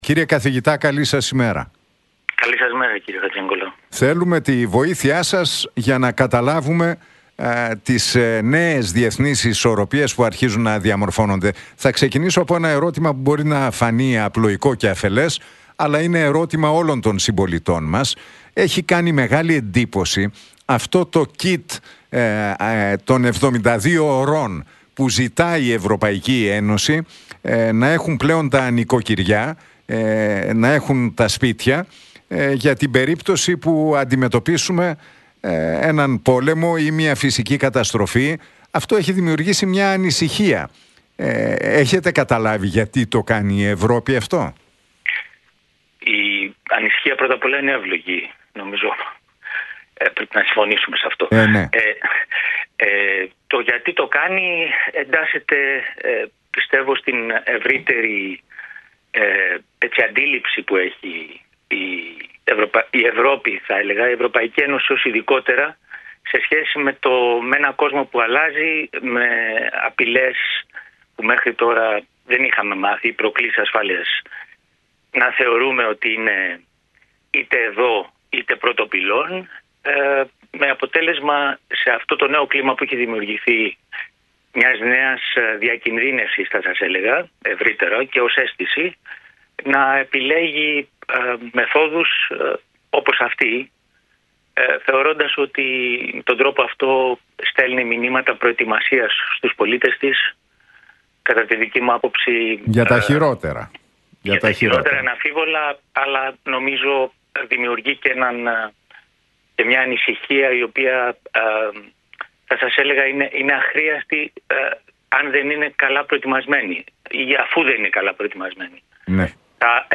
Σε ερώτηση για το εάν η Τουρκία θα παίξει ρόλο στην ευρωπαϊκή άμυνα και ασφάλεια, ο καθηγητής απάντησε: